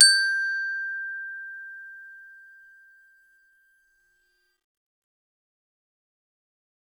glock_medium_G5.wav